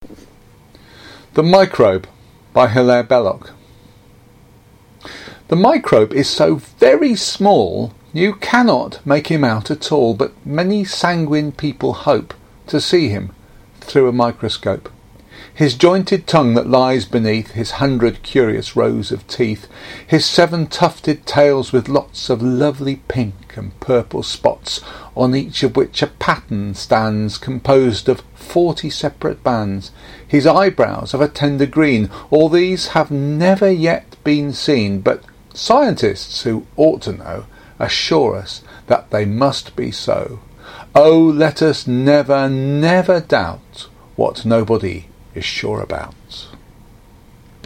Here are some examples of me reading verse and pros by others:-